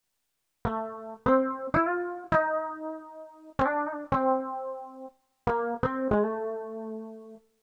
Инструмент — Fretles! (бас).
Чрезвычайно высокий шум, полное несоответствие обработки эффектами.